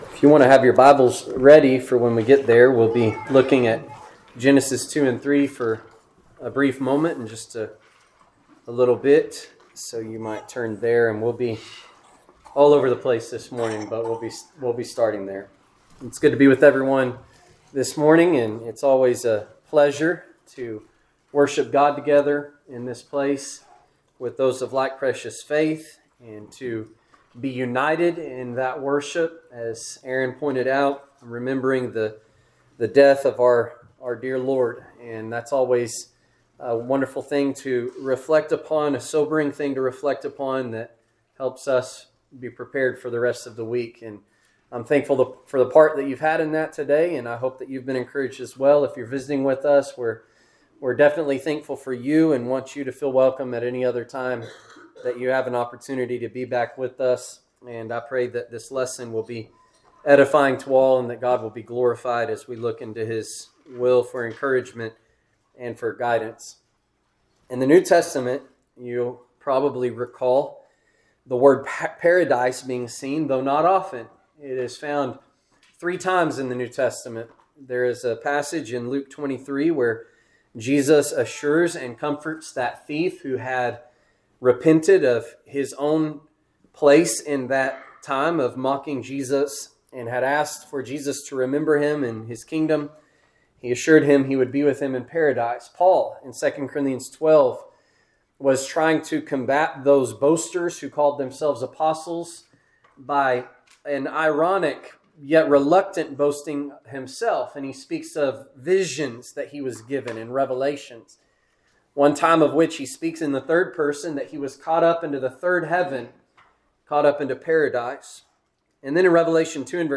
Seasonal Preaching Sermon Podcast